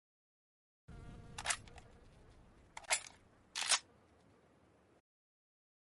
Awm Reload Sound Effect Free Download
Awm Reload